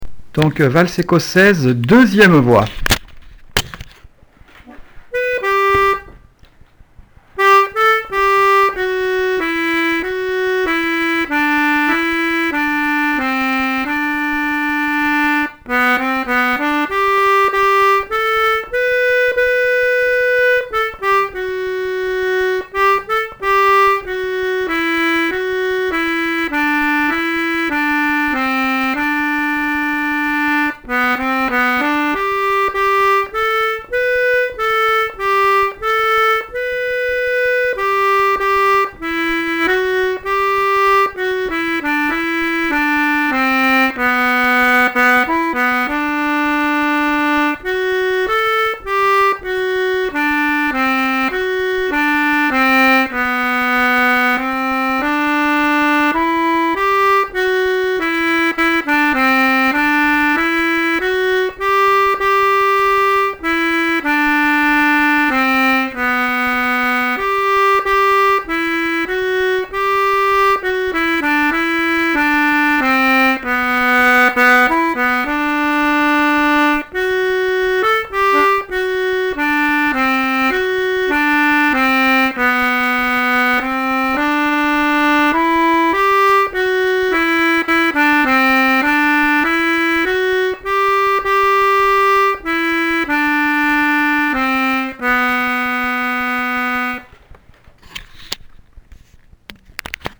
l'atelier d'accordéon diatonique
seconde voix
valse ecossaise 2eme voix.mp3